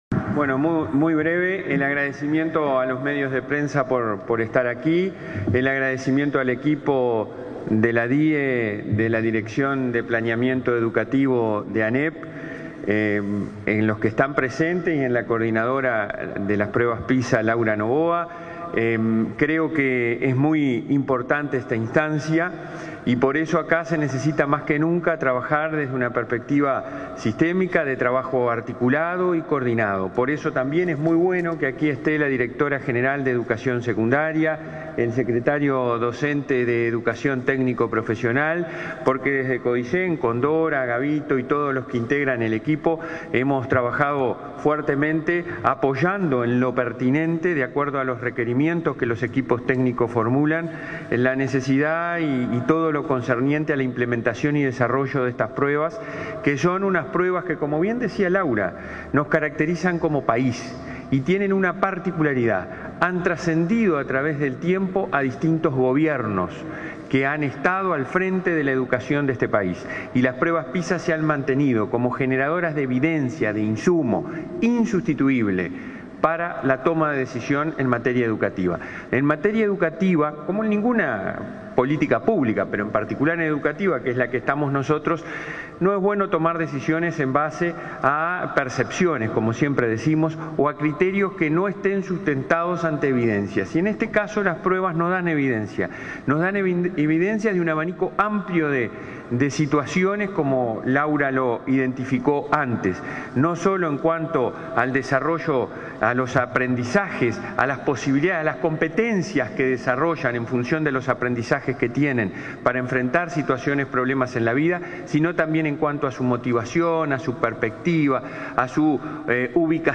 Palabras del presidente del Codicen de la ANEP, Robert Silva
Palabras del presidente del Codicen de la ANEP, Robert Silva 12/08/2022 Compartir Facebook X Copiar enlace WhatsApp LinkedIn Las autoridades de la educación, incluido el presidente del Consejo Directivo Central (Codicen) de la Administración Nacional de Educación Pública, Robert Silva, y los directores generales de los subsistemas, informarán en conferencia de prensa, este 12 de agosto, sobre la aplicación de las pruebas PISA 2022.